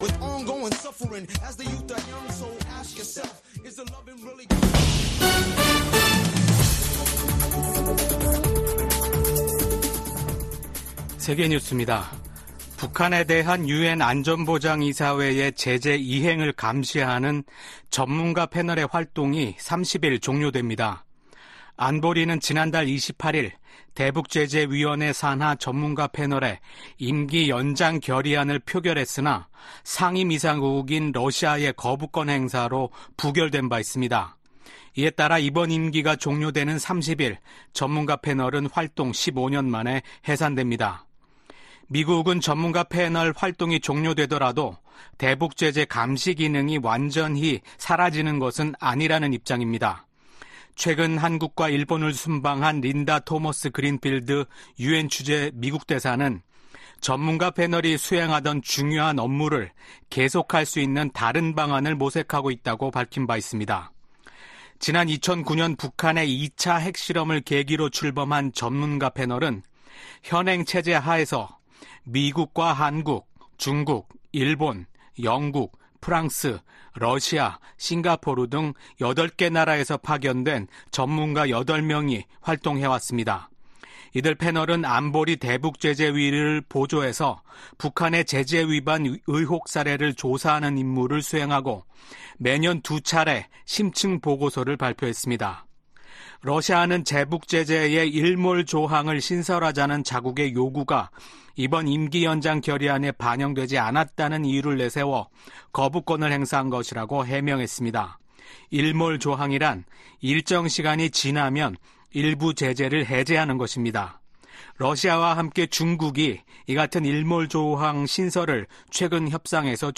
VOA 한국어 아침 뉴스 프로그램 '워싱턴 뉴스 광장' 2024년 5월 1일 방송입니다. 토니 블링컨 미 국무장관은 중국과, 북한, 이란이 우크라이나와 전쟁 중인 러시아를 직 간접적으,로 지원하고 있다고 비판했습니다.